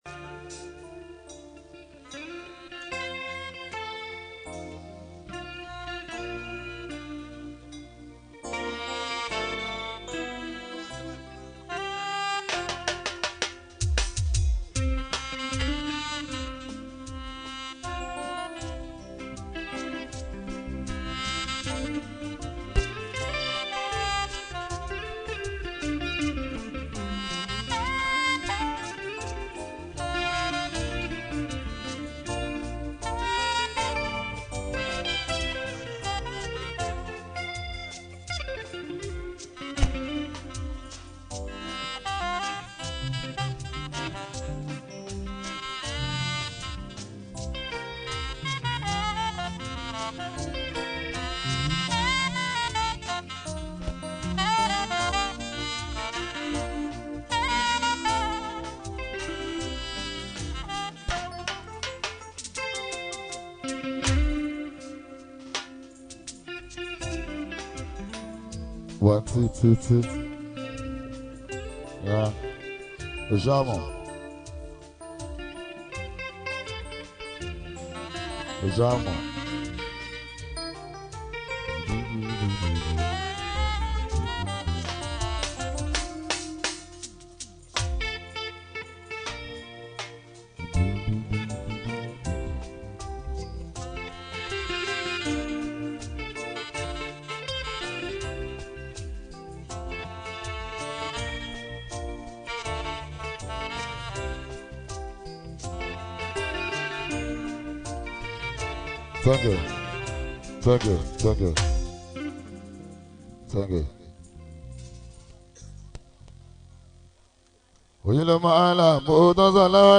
Yoruba Fuji song
Fuji song